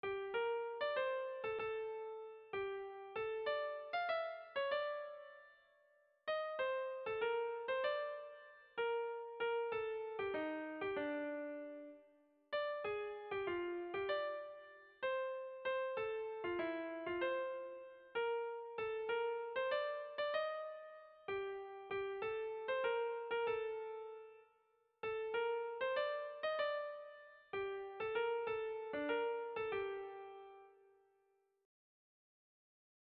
Kantu eta pianorako egokitutako doinua.
Zortziko txikia (hg) / Lau puntuko txikia (ip)
ABDE